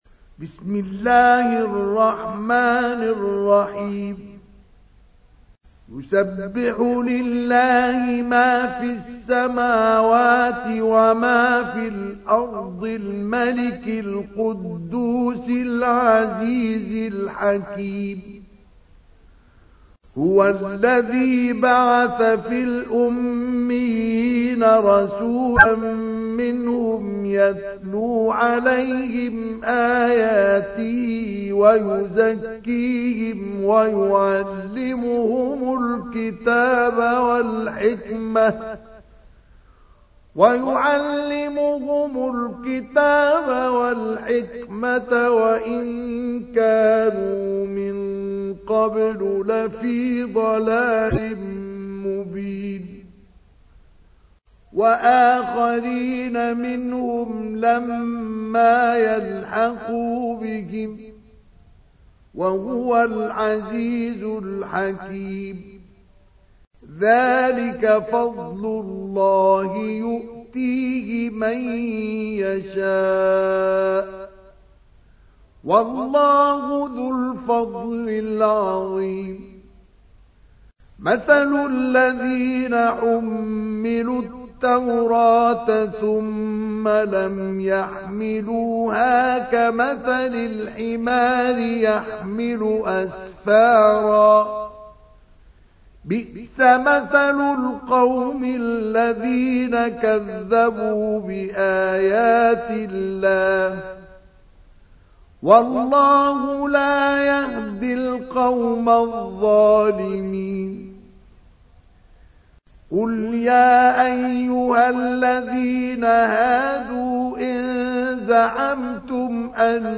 تحميل : 62. سورة الجمعة / القارئ مصطفى اسماعيل / القرآن الكريم / موقع يا حسين